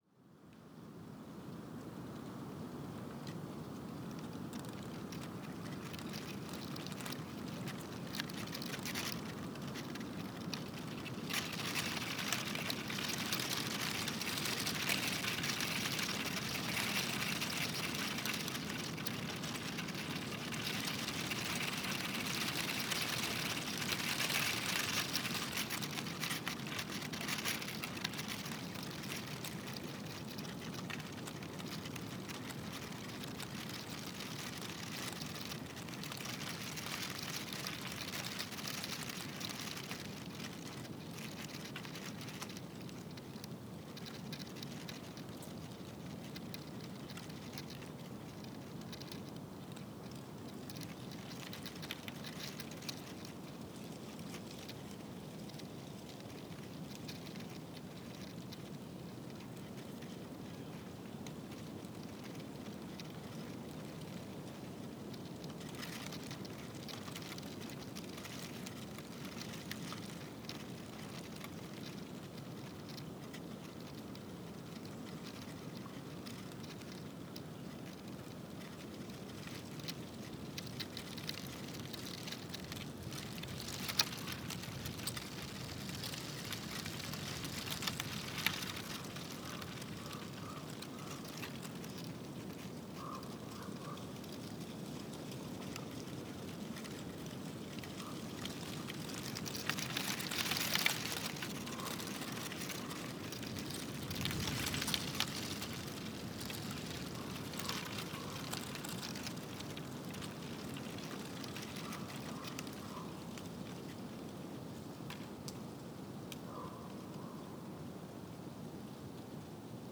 Wind blowing over dead leaves on a Beech | Mont-Tremblant National Park
Late afternoon in the forest. Winter wind blowing on dead leaves still hanging from the branches of a young American Beech (Fagus grandifolia).
Birds heard : Red-breasted Nuthatch, Blue Jay, Downy Woodpecker, Common Raven, Black-capped Chickadee.
Mont-Tremblant National Park, QC.